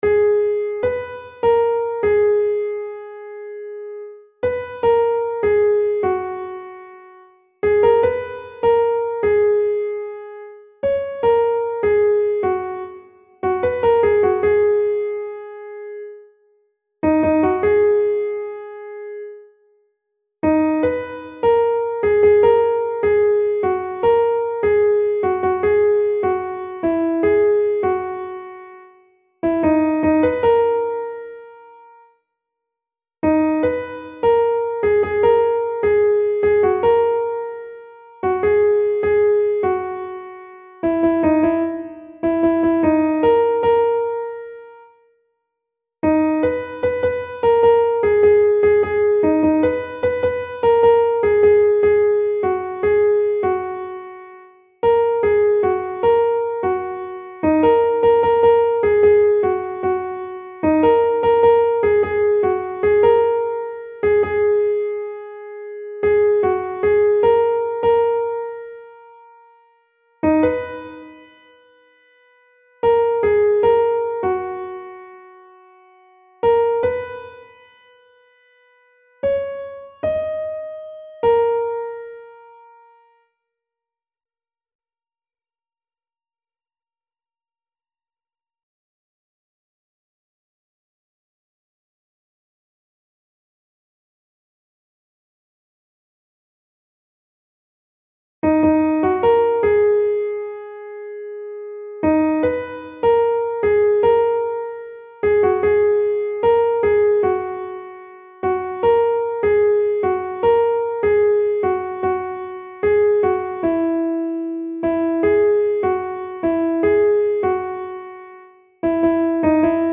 نت کیبورد
این محصول شامل دو نسخه (گام اصلی+گام ساده) می باشد